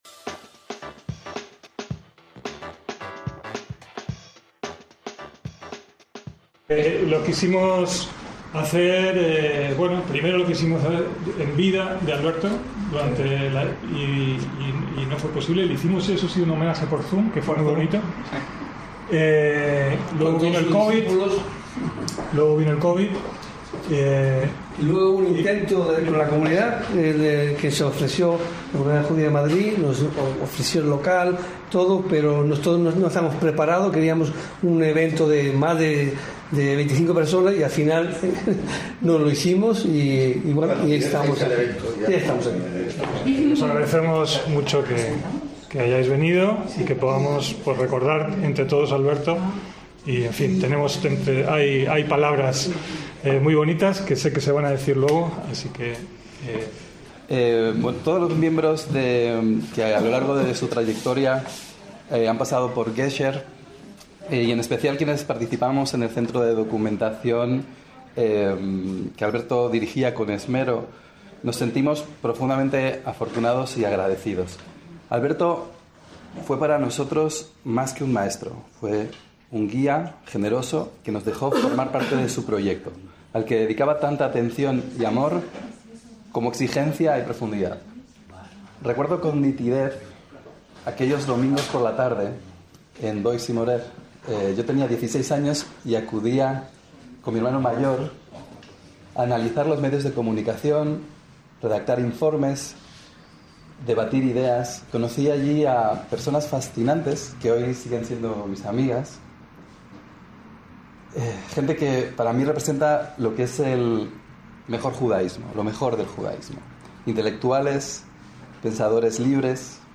ACTOS EN DIRECTO
En la reunión participaron muchos compañeros de fatigas que nos dejan su testimonio oral.